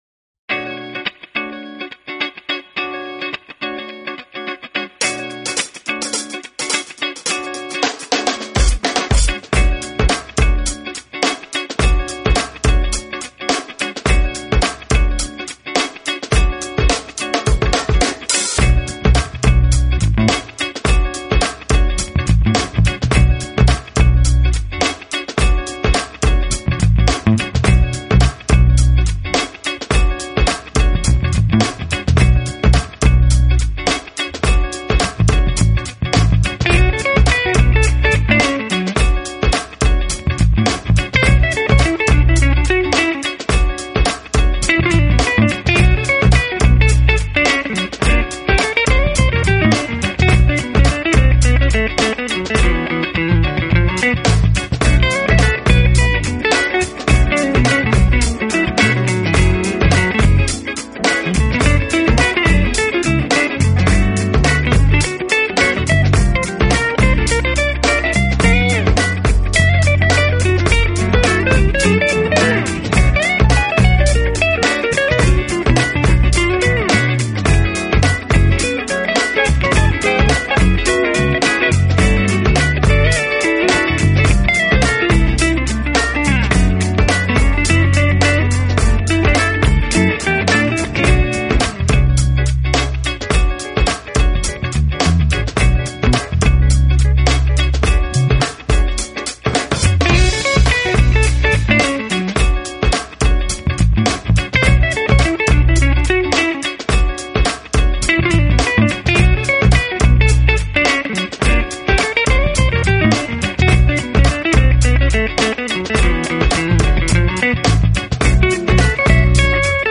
Funk Jazz